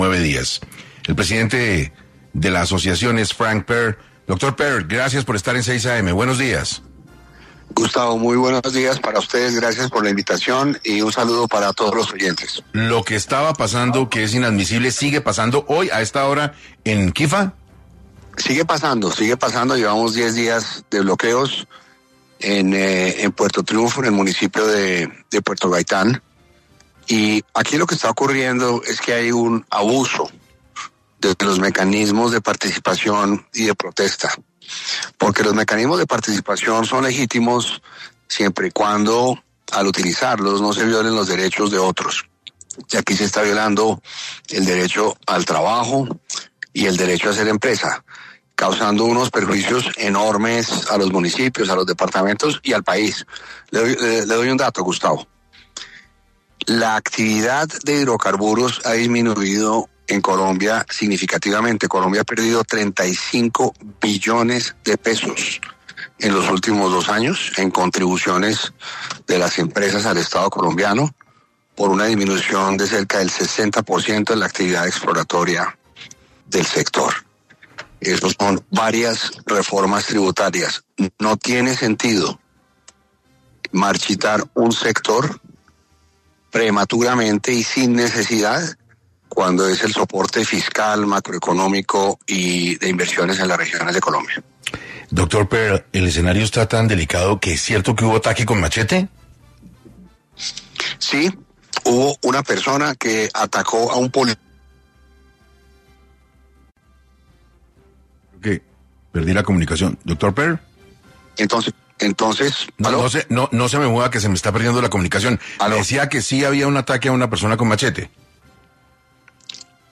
El presidente de la Asociación Colombiana del Petróleo y Gas, Frank Pearl, habló en entrevista para 6AM, sobre la problemática que atraviesa el sector de hidrocarburos y cómo esta afecta el futuro del país.